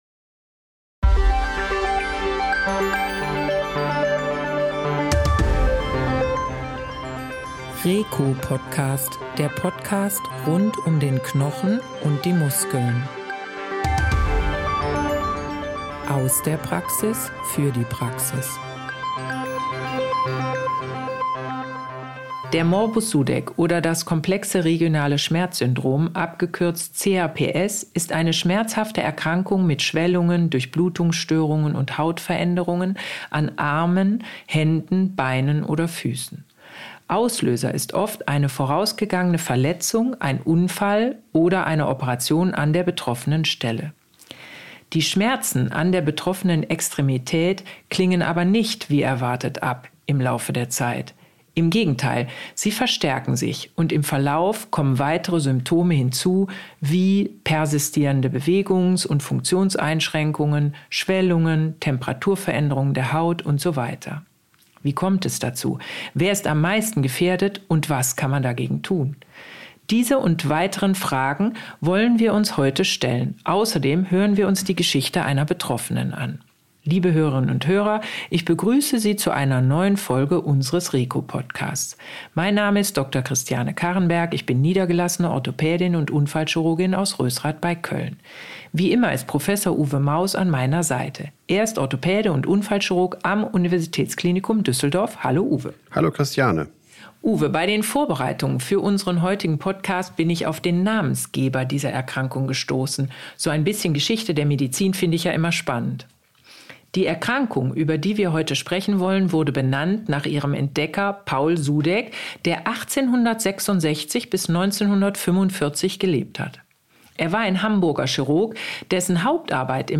Die vielfältigen Symptome, die Diagnostik und die möglichen Therapieoptionen sind das Thema in diesem Podcast. Dabei ist auch eine Betroffene dabei und berichtet aus ihrer Sicht.